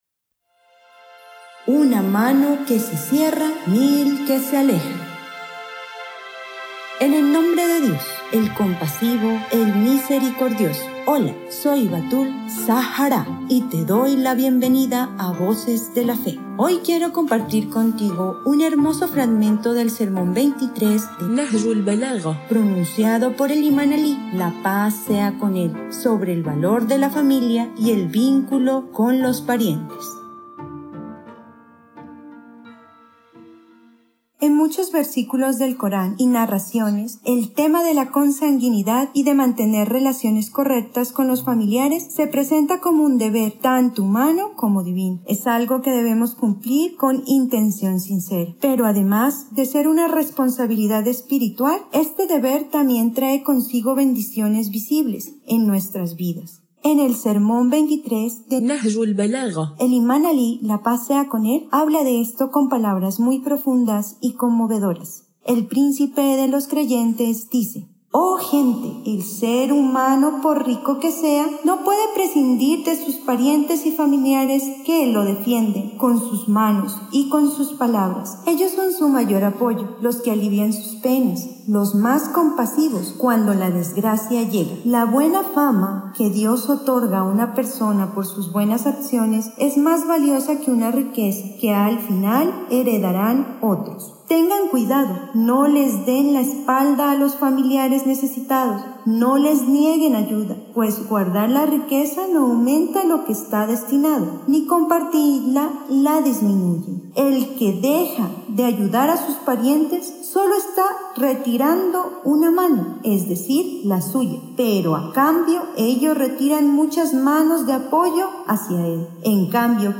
🎙 Locutora: